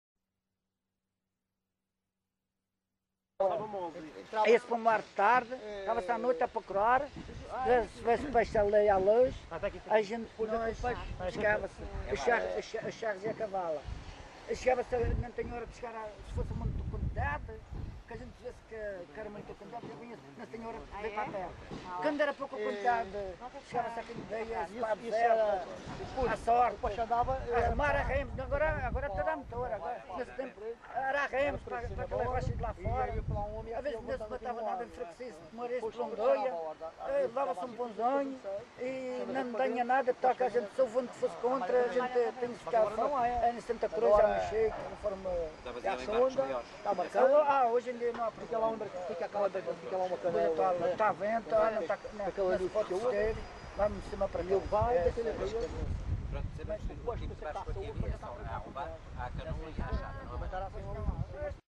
LocalidadeCâmara de Lobos (Câmara de Lobos, Funchal)